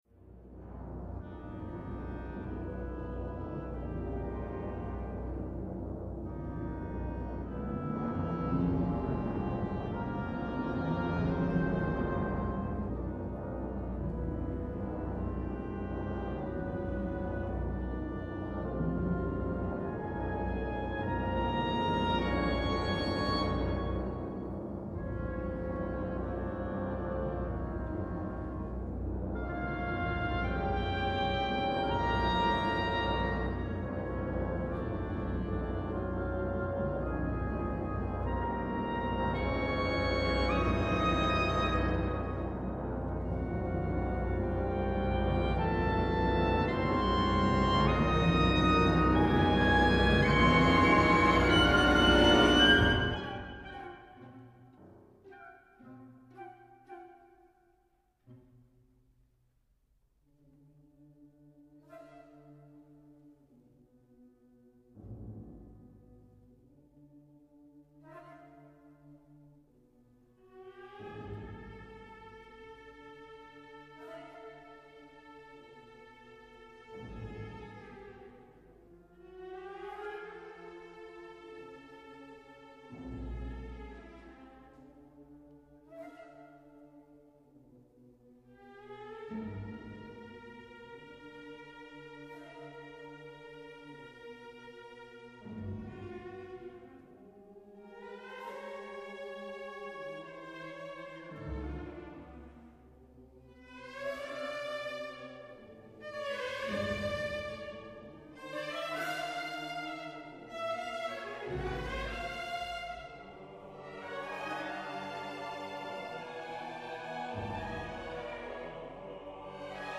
It is during the last of the threefold attempts to kill the Mandarin—mirroring the three encounters with the potential victims—that Bartók includes hidden wordless chorus (SATB).
At this moment the chorus enters singing the vowel “O” for nine measures, then an additional ten measures on the vowel “A” (see Example).